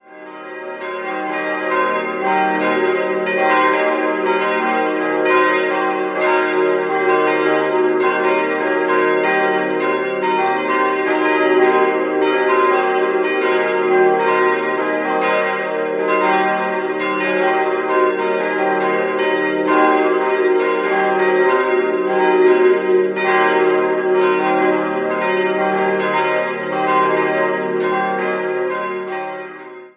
4-stimmiges Salve-Regina-Geläute: es'-g'-b'-c''
Die vier Glocken wurden 1996 von der Gießerei Bachert gegossen.
Die beiden großen Glocken sind mit Reversionsklöppeln ausgestattet.